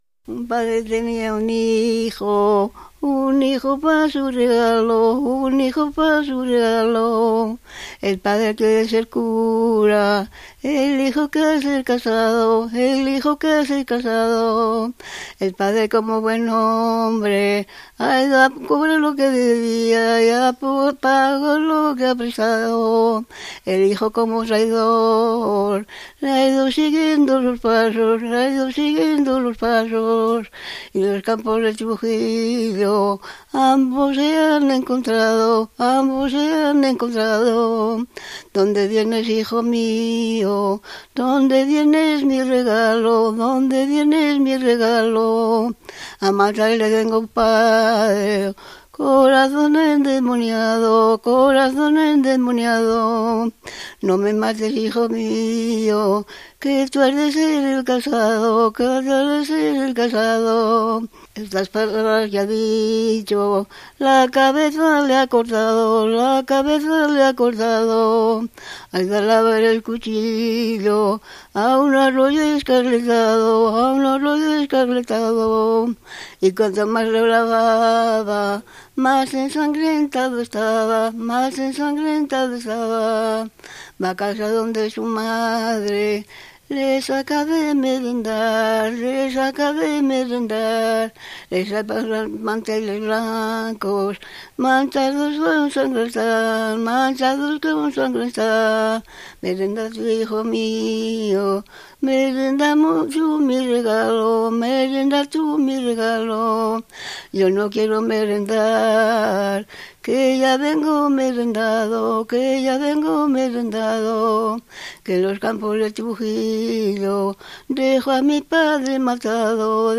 Clasificación: Romancero
Lugar y fecha de recogida: Neila, 18 de abril de 1998